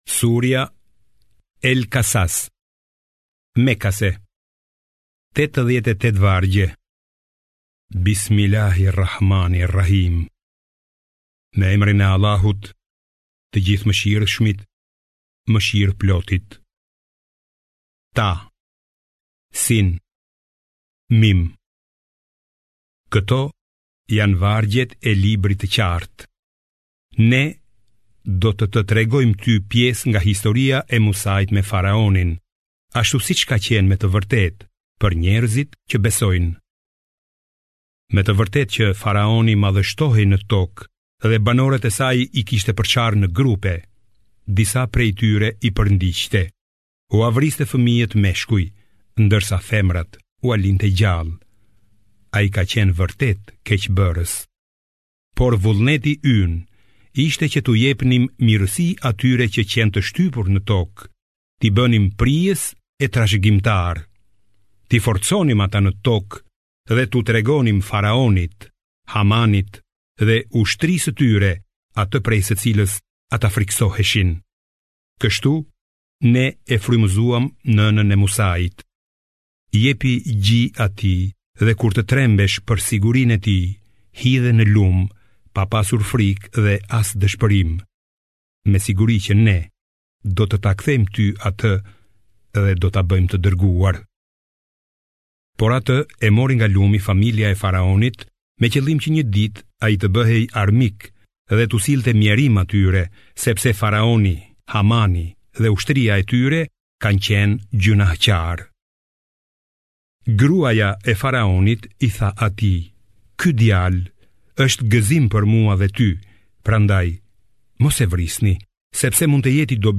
028 SURJA EL-KASAS - KURANI i madhërishëm - Përkthimi audio i kuptimeve të Kuranit Fisnik në shqip
Audio translation of the meanings of the Noble Quran in Albanian